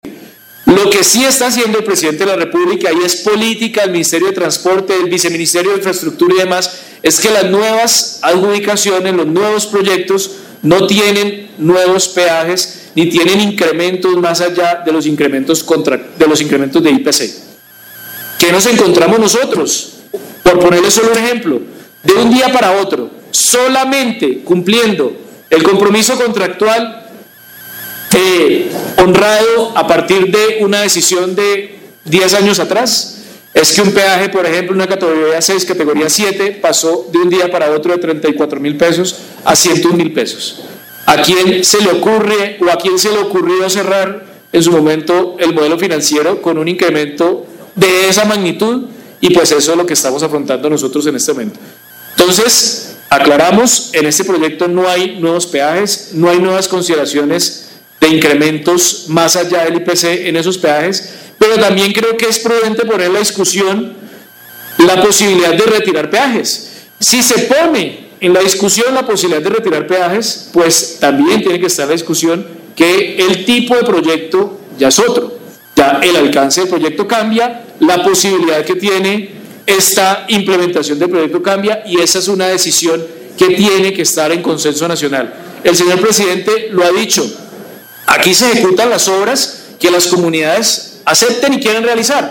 Así lo ratificó Francisco Ospina, Presidente de la Agencia Nacional de Infraestructura durante debate en la Cámara de Representantes.
Francisco Ospina Presidente de la ANI